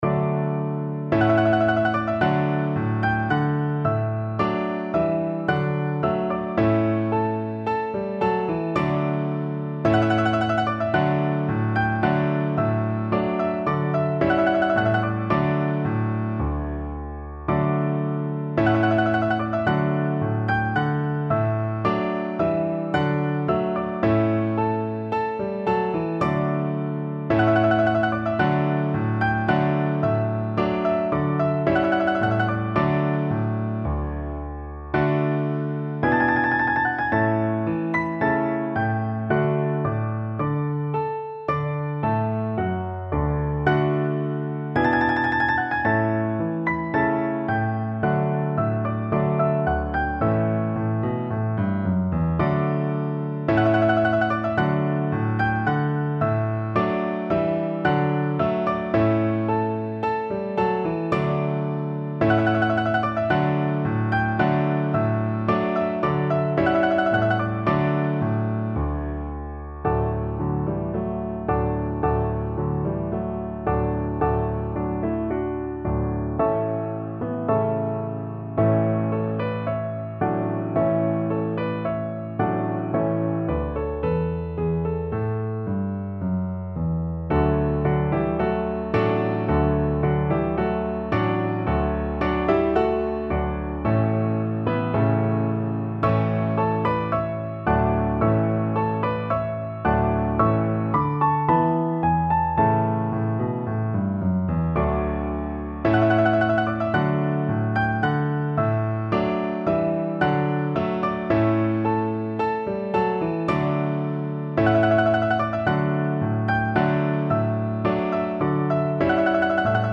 Free Sheet music for Piano Four Hands (Piano Duet)
4/4 (View more 4/4 Music)
Allegro moderato =110 (View more music marked Allegro)
Classical (View more Classical Piano Duet Music)